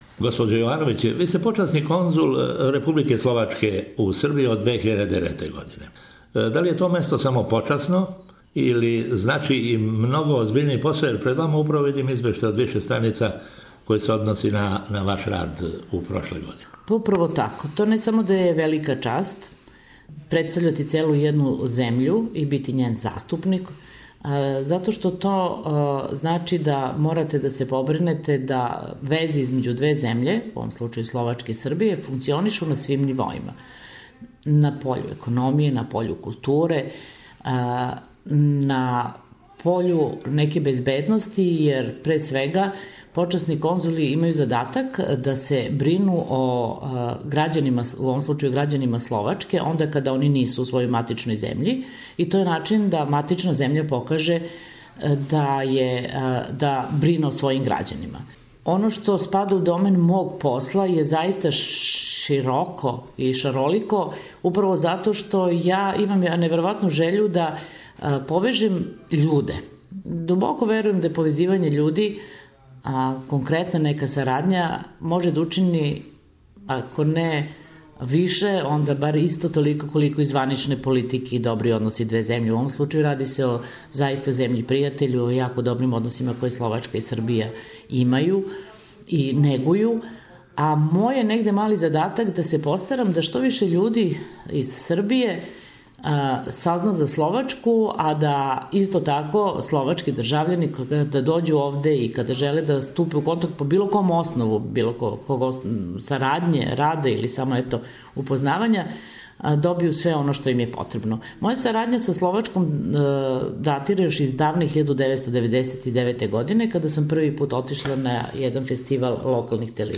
Почасни конзул Републике Словачке у Србији Стела Јовановић говори о односима две земље и специјалним релацијама међу градовима Ниш и Кошице.